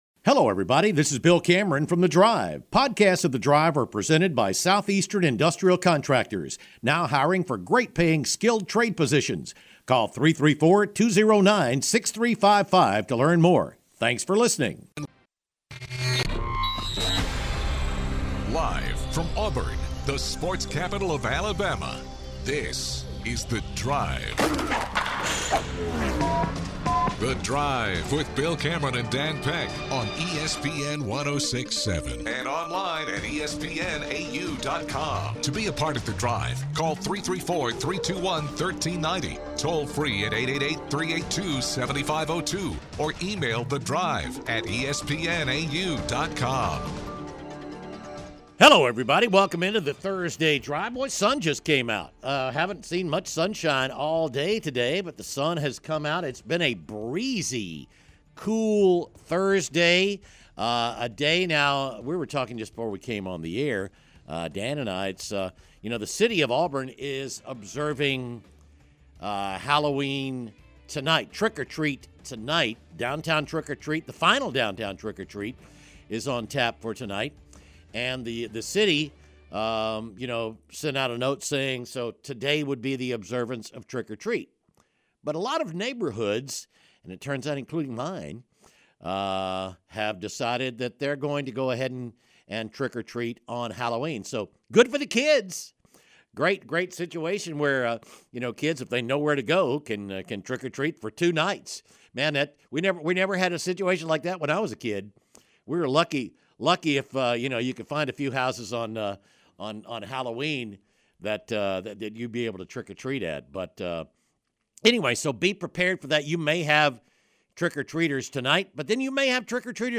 Callers ask about how the coaching market should influence the decision to make a coaching change.